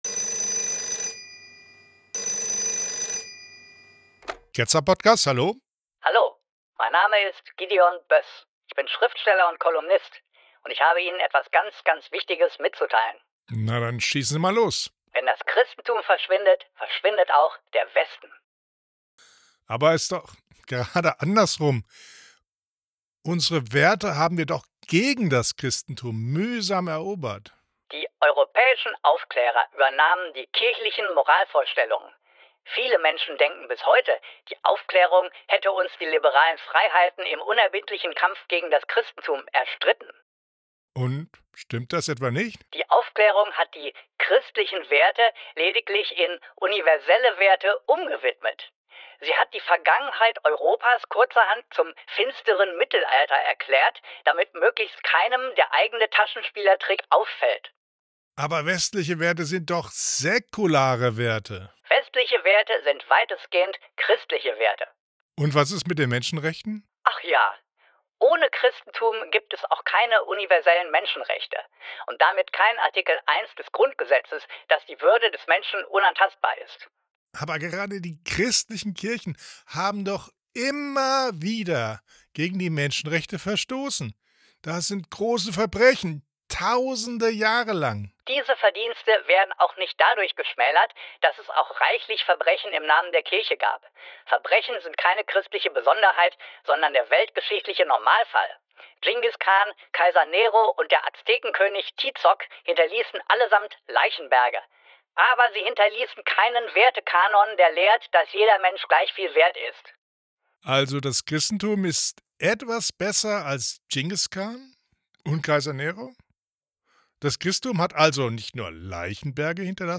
Ein wahrhaft dummer Anruf!